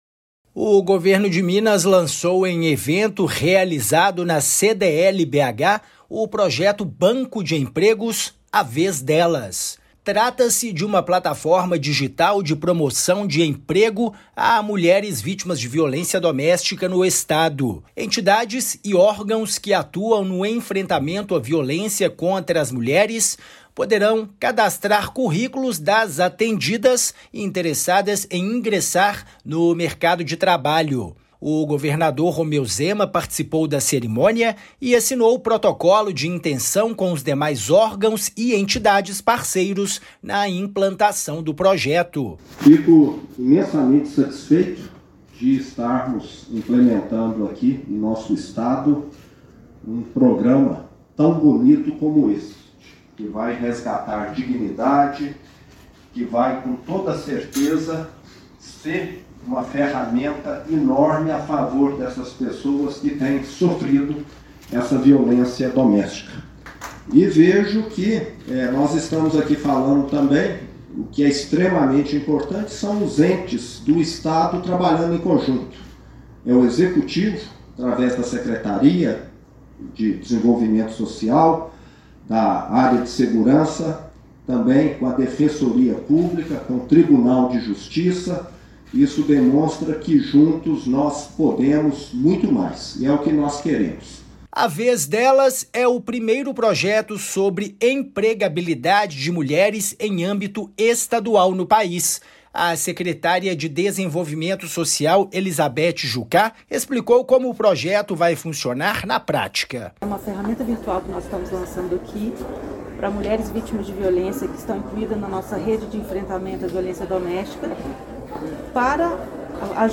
[RÁDIO] Governo de Minas lança projeto “A Vez Delas”, banco de vagas para mulheres vítimas de violência
MATÉRIA_RÁDIO_A_VEZ_DELAS.mp3